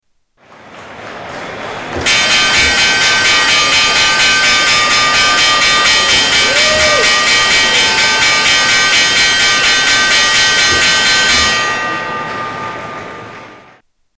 FX - NY Stock Exchange Bell